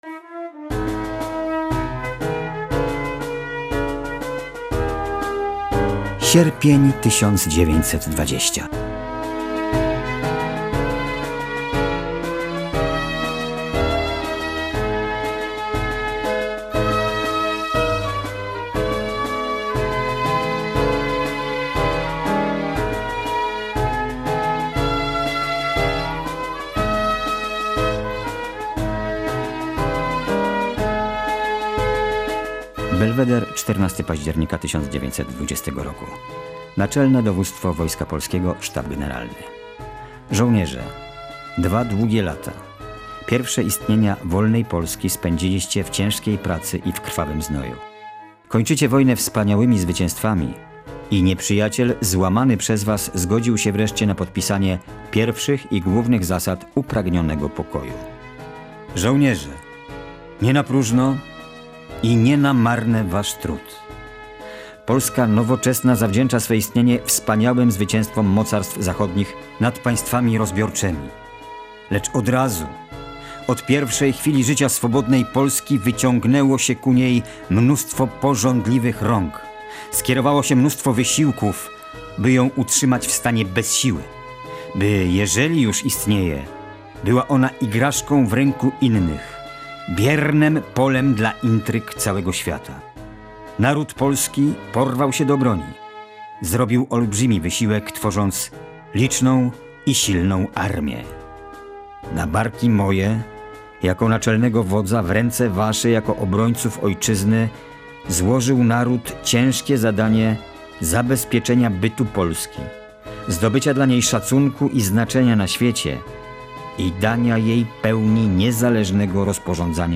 100. rocznica Bitwy Warszawskiej i zakończenia wojny polsko-bolszewickiej.
15 sierpnia mija 100. rocznica Bitwy Warszawskiej i zakończenia wojny polsko-bolszewickiej. Z tej okazji podczas spotkania z historią na naszej antenie pojawiła się odezwa Marszałka Józefa Piłsudskiego, a także wiersze i piosenki z tamtych czasów.